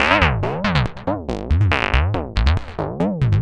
tx_synth_140_mhhahh2.wav